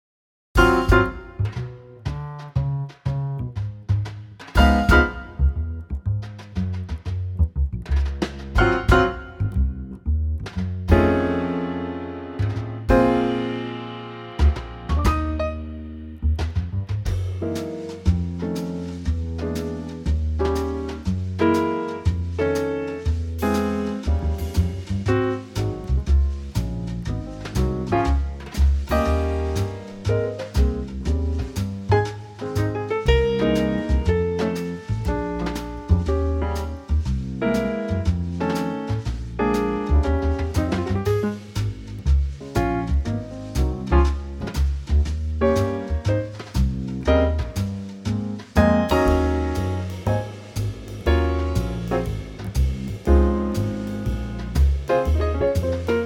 Unique Backing Tracks
key - Ab - vocal range - Eb to Gb
Superb Trio arrangement